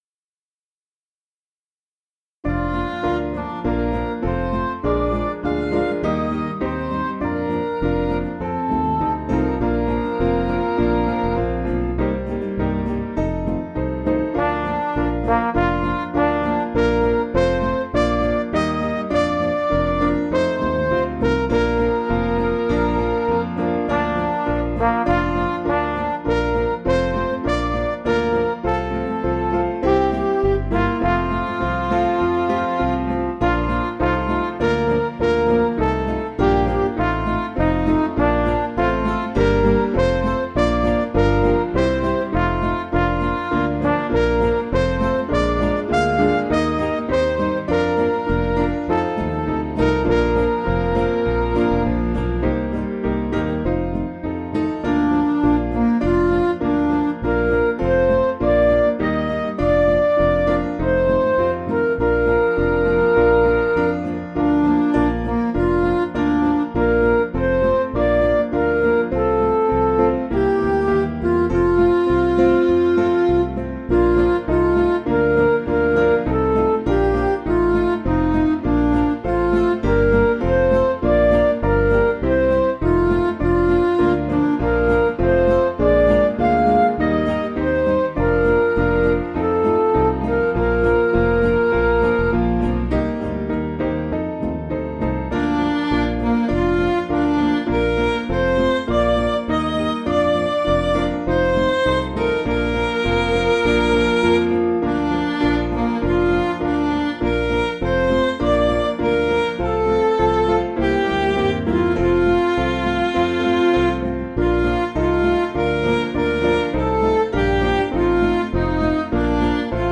Piano & Instrumental
(CM)   4/Bb